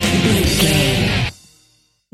Aeolian/Minor
drums
electric guitar
bass guitar
Sports Rock
hard rock
metal
angry
lead guitar
aggressive
energetic
intense
powerful
nu metal
alternative metal